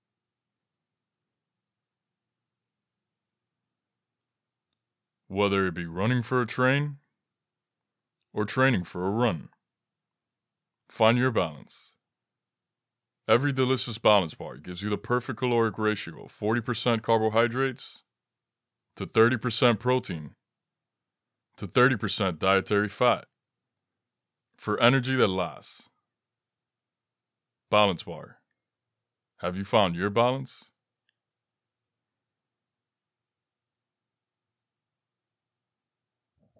Balance Bar Nutritional Snack Online Ad
Young Adult
I have a naturally strong, clear voice with a broad range. My voice is primarily warm and smooth, yet authoritative with an intellectual subtleness.